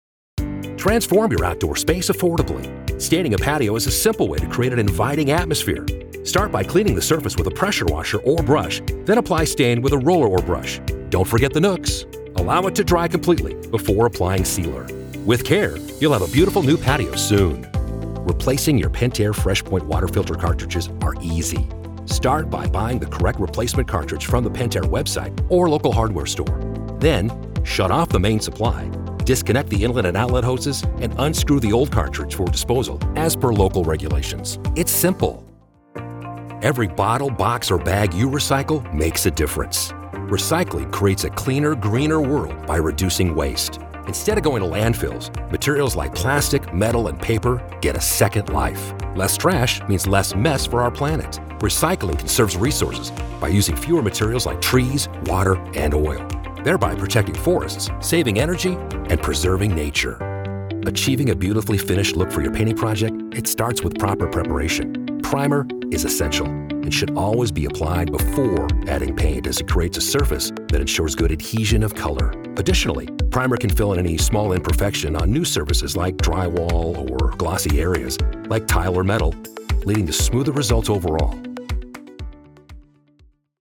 Male
Adult (30-50), Older Sound (50+)
I deliver a warm, friendly, relatable tone with clarity and articulation. I also possess a versatile and dynamic range that is energetic and enthusiastic, as well as authoritative and confident.
Explainer Videos